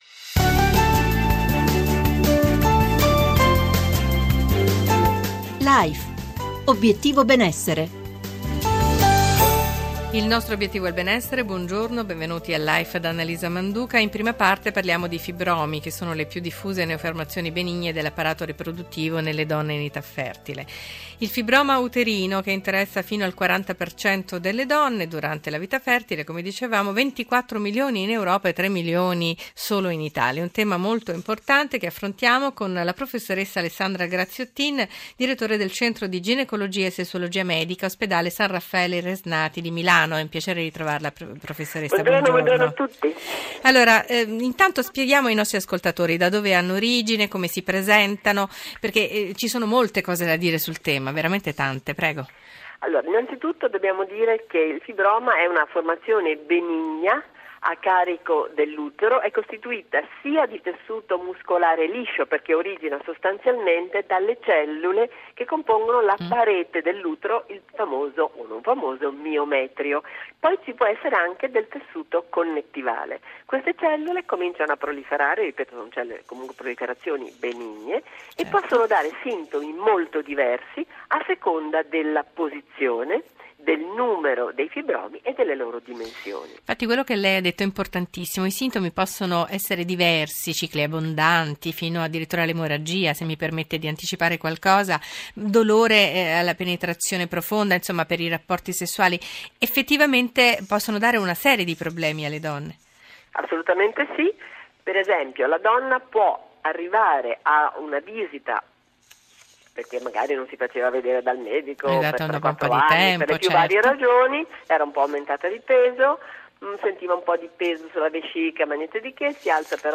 Sintesi dell'intervista e punti chiave I fibromi uterini sono le più diffuse neoformazioni benigne dell’apparato riproduttivo della donna in età fertile: interessano 24 milioni di donne in tutta Europa e ben tre milioni solo nel nostro Paese.